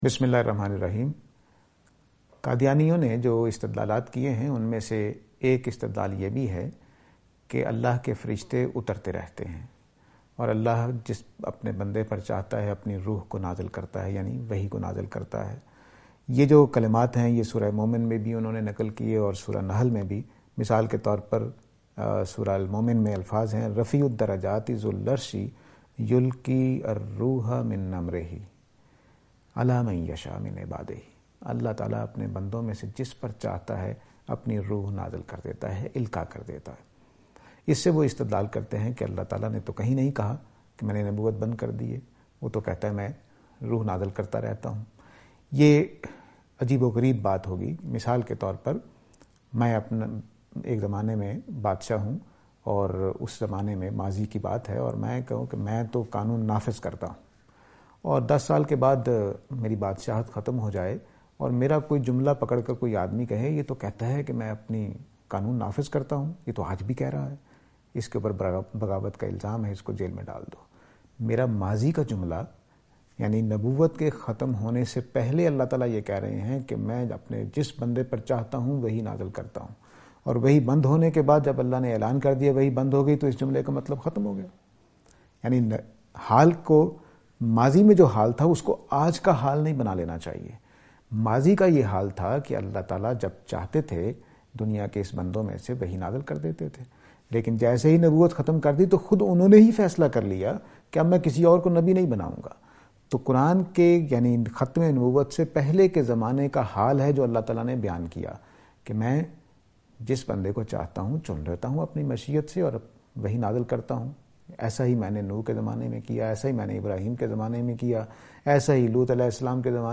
This lecture series will deal with Reviewing Qadiyani Discourse .